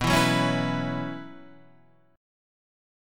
B Suspended 2nd Suspended 4th